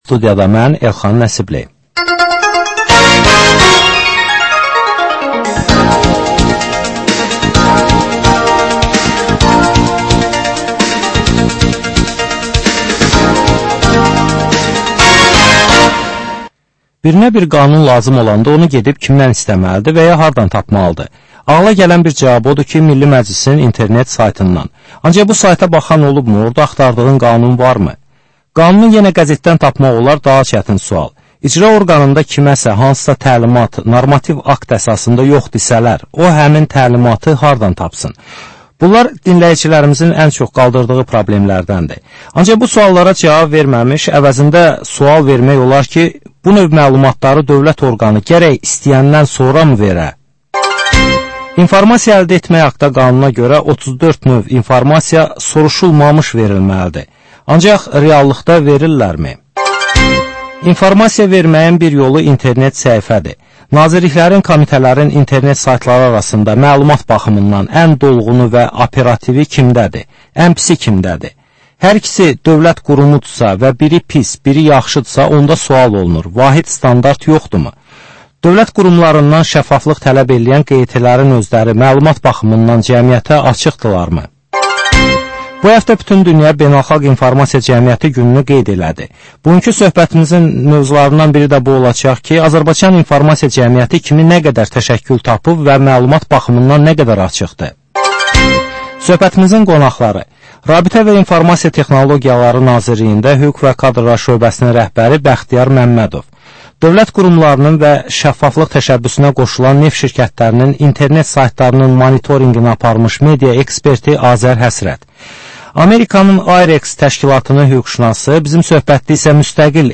Aktual mövzu barədə canlı dəyirmi masa söhbəti.